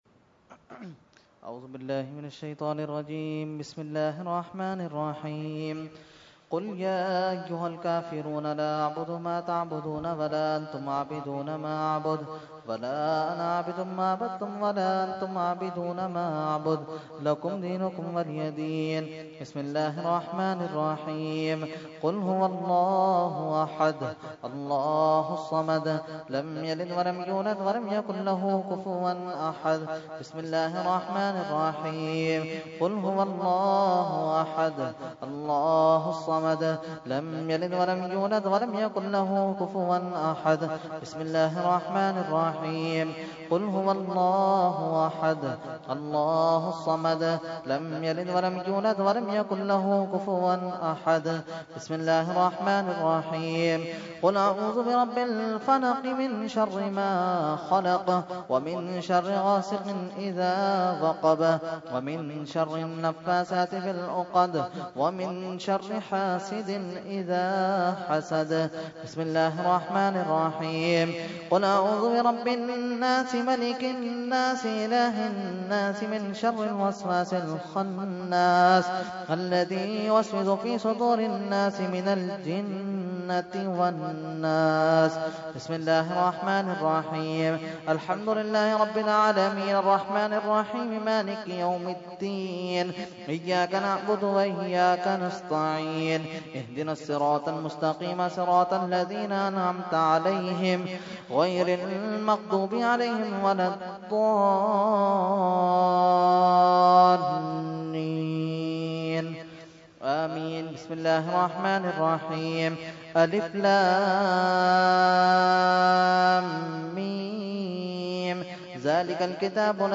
Fatiha Dua – Urs Ashraful Mashaikh 2016 – Dargah Alia Ashrafia Karachi Pakistan
22-Fatiha and Dua.mp3